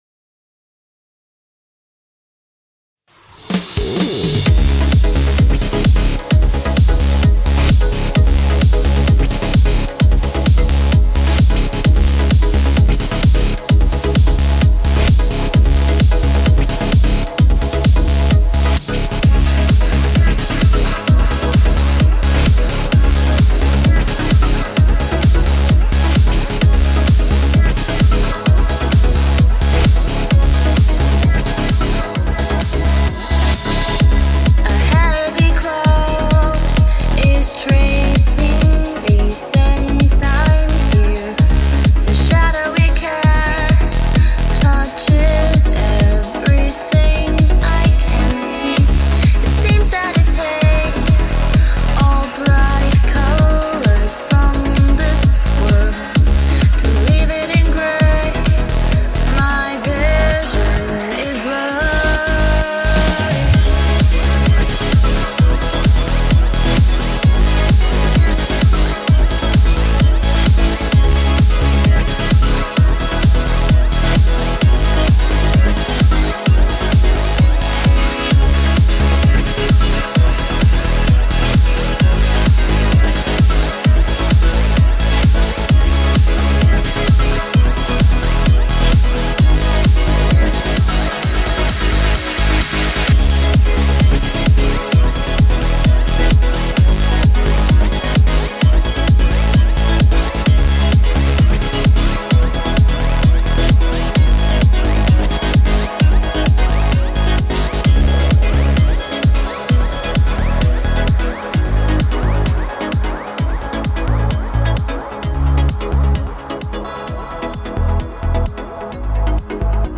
I just felt like doing some depressive trance.